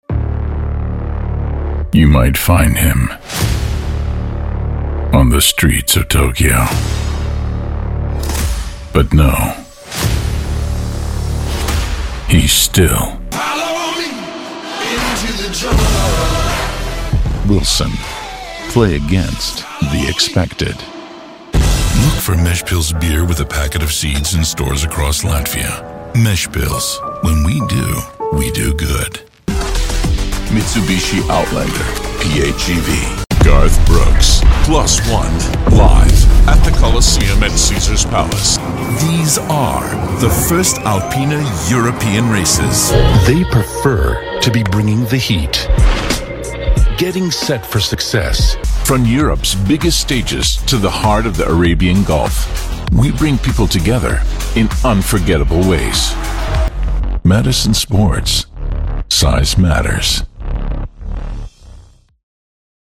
Never any Artificial Voices used, unlike other sites.
English (British)
Yng Adult (18-29) | Adult (30-50)